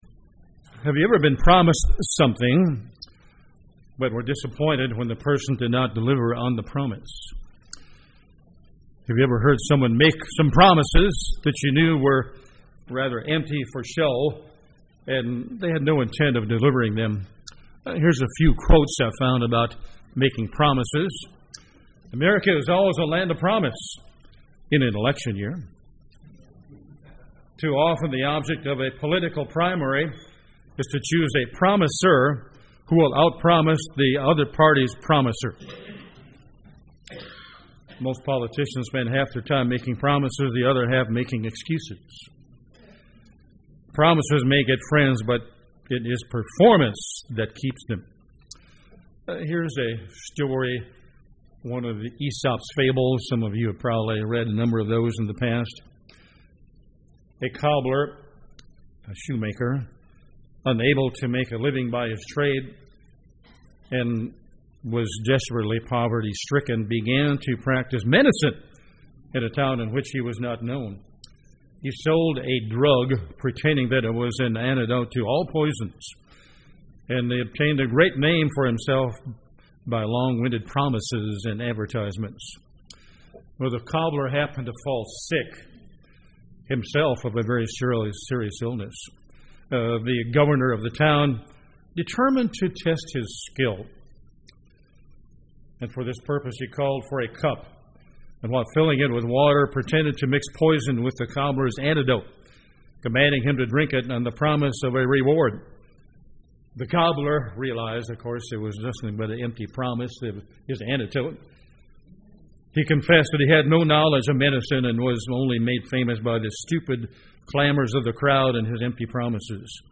Review of the promises God hs made to His firstfruits called to be in His kingdom. This message was given on the Feast of Pentecost.
UCG Sermon Studying the bible?